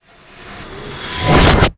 magic_conjure_charge1_04.wav